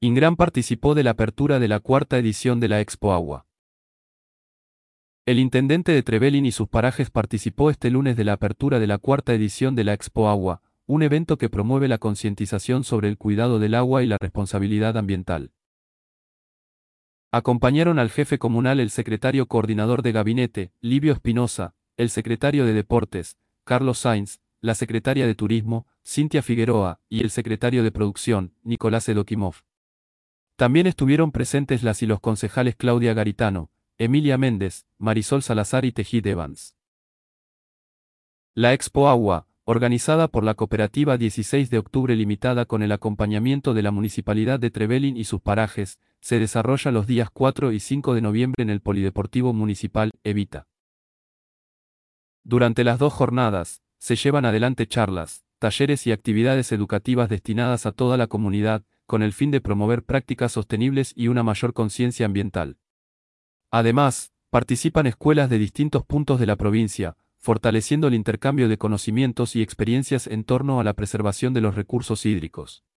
apertura_expo_agua.mp3